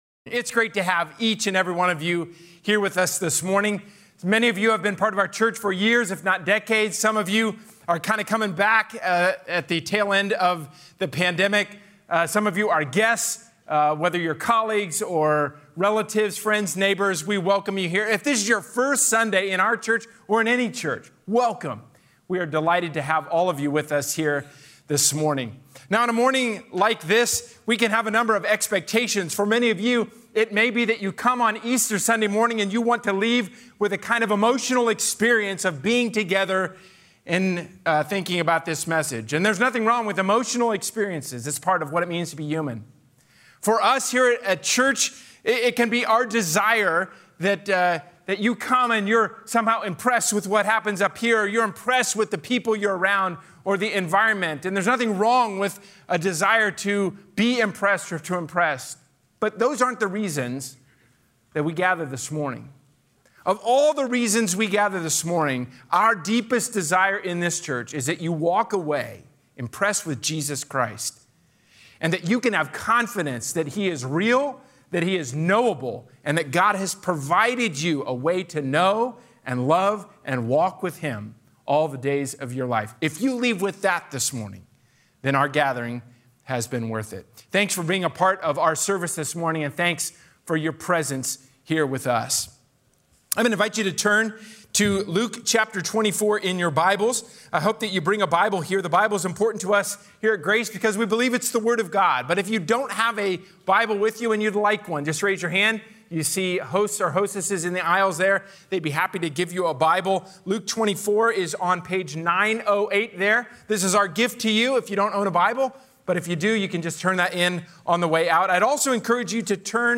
A sermon from the series "The Great Reversal."